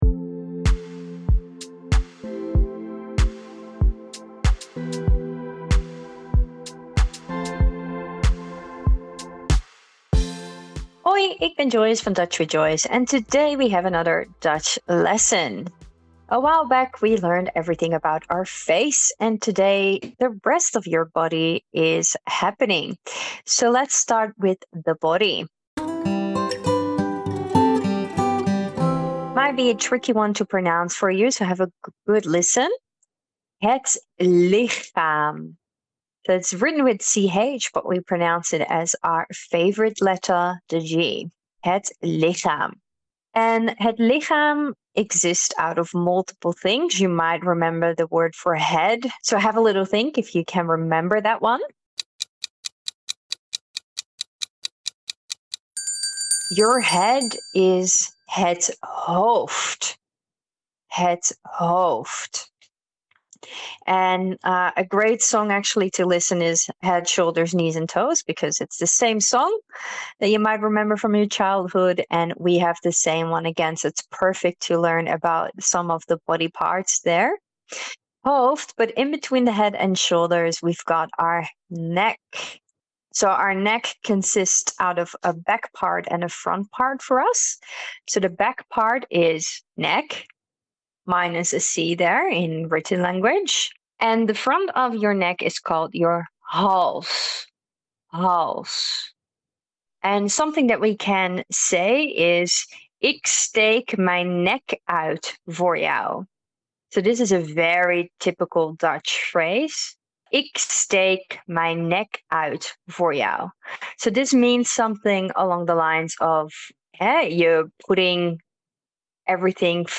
Dutch mini-lesson